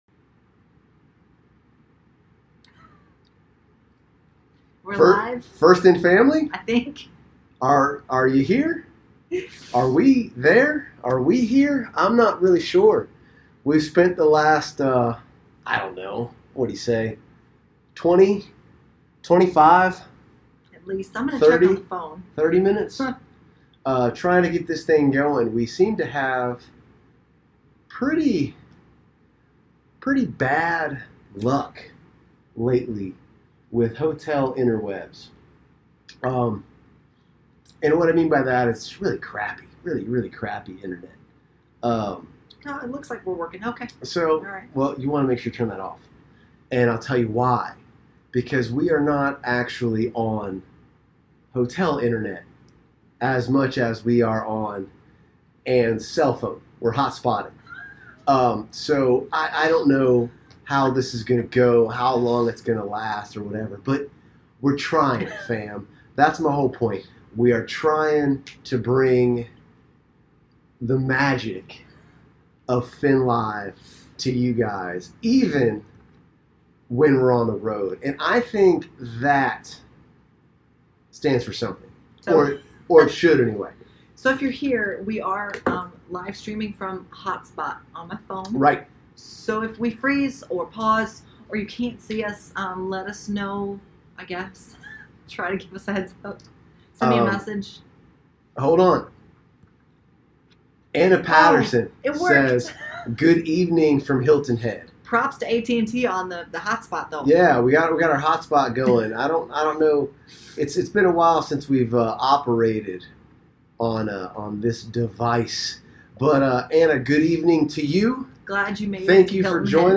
Show Notes Some technical difficulties in this on the road edition of the Q+A kept it short, but we still had time to get in a little rant about how to be a good human, how not to treat your coaches, and how excited I am about eating White Castle before it got too bad!